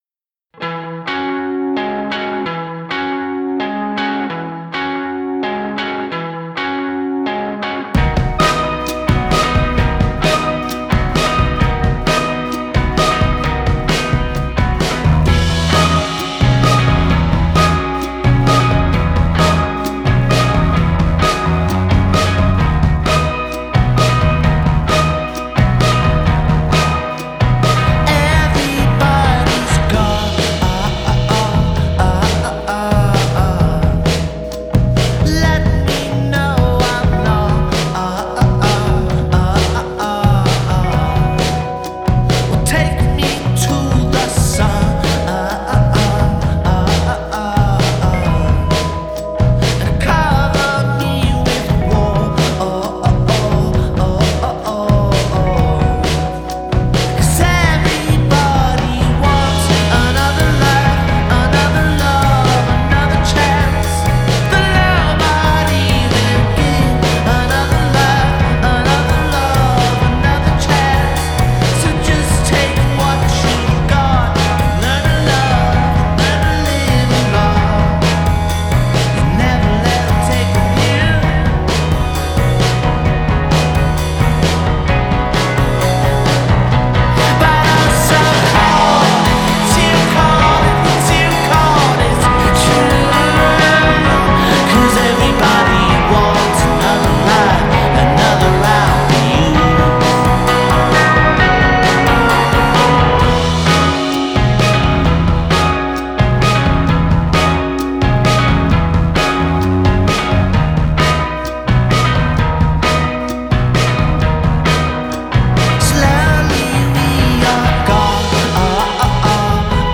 driving psych-folk passage